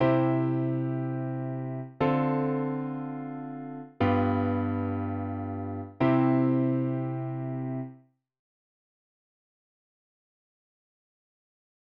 Fig.002a 和声(harmony)の一例 上記は下記Fig.002bの楽譜と同じ譜です。
記の譜は、高さの異なる4つの声部が、4つ(4小節にわたり)つながっている。
ex1harmony.wav